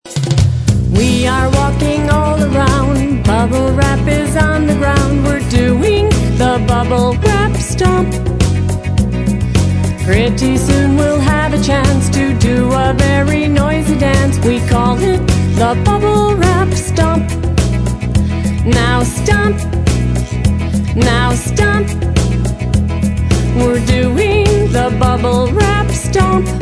Action Song Lyrics